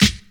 • 90s Treble-Heavy Hip-Hop Steel Snare Drum G Key 128.wav
Royality free snare sound tuned to the G note. Loudest frequency: 2436Hz
90s-treble-heavy-hip-hop-steel-snare-drum-g-key-128-QiC.wav